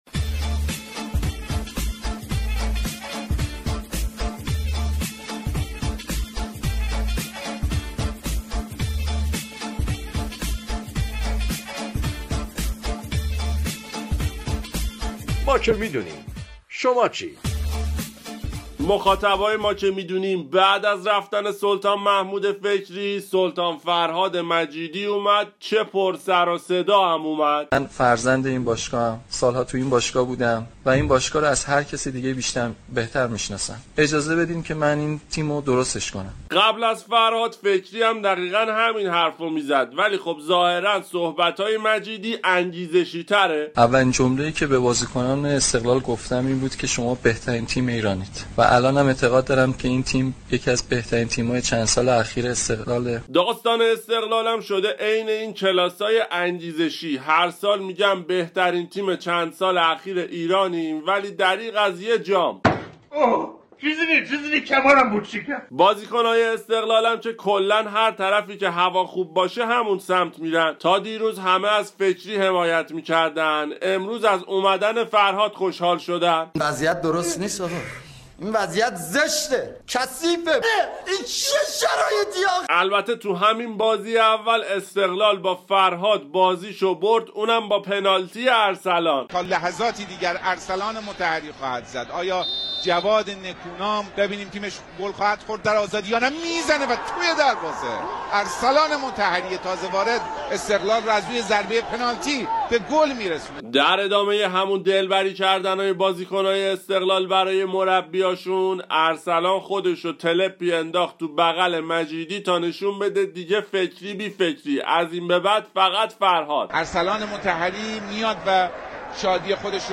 سوژه اصلی آیتم طنز ما که می دونیم برنامه از فوتبال چه خبر رادیو ورزش، برگشت فرهاد مجیدی به استقلال و پیروزی آبی ها مقابل فولاد خوزستان بود